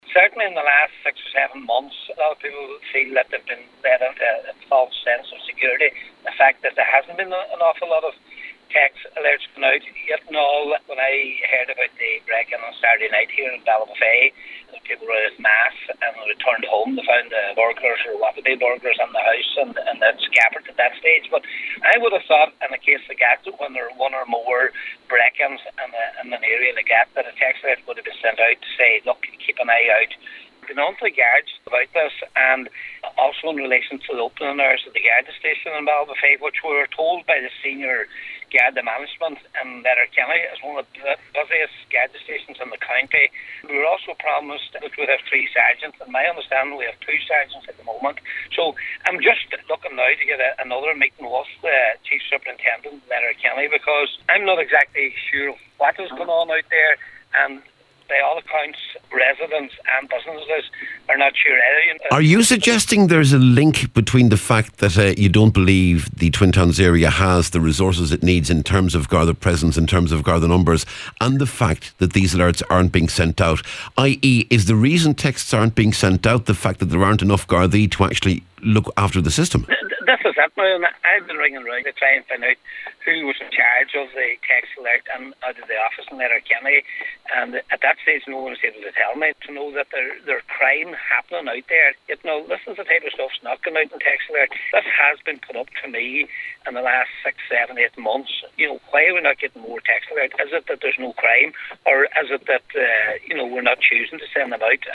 Cathaoirleach of the Stranorlar Municipal District, Councillor Patrick McGowan says people have been given a false sense of security: